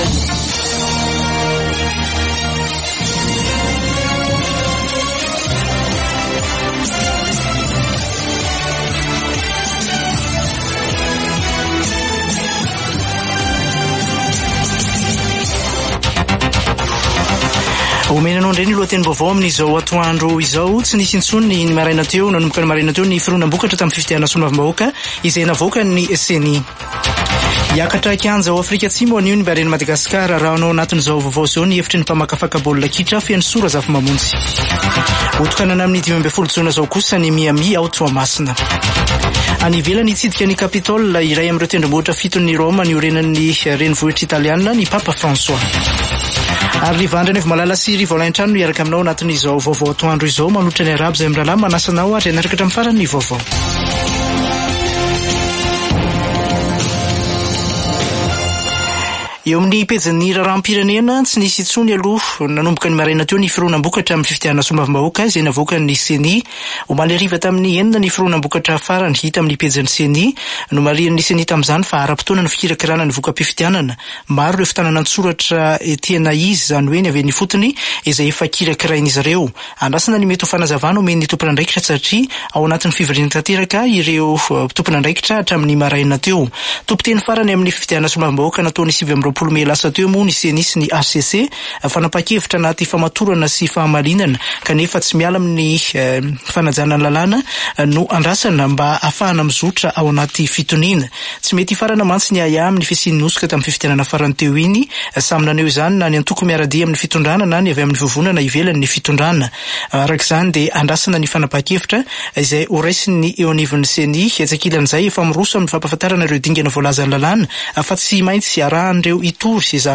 [Vaovao antoandro] Zoma 7 jona 2024